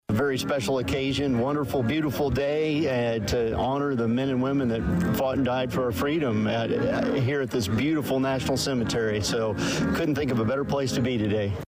During the annual Memorial Day Ceremony at Danville National Cemetery, State Representative Mike Marron and numerous speakers called for unity among American citizens while working out differences.  Marron said beforehand, there is no better place to be, to have the proper perspective on Memorial Day.